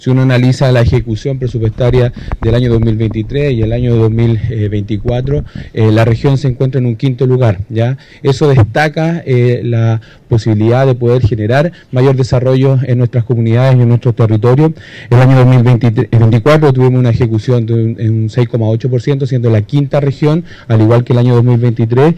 Los representantes del Ejecutivo se reunieron en el piso 12 del Gobierno Regional, liderados por el delegado Presidencial, Eduardo Abdala, para dar cuenta del progreso logrado en la zona y todo el país.
El seremi de Hacienda en La Araucanía, Ronald Kliebs, destacó que la región se ubica quinta a nivel nacional respecto a la ejecución presupuestaria en 2024.